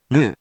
We’re going to show you the character, then you you can click the play button to hear QUIZBO™ sound it out for you.
In romaji, 「ぬ」 is transliterated as 「nu」which sounds likenew」if you’re from the states.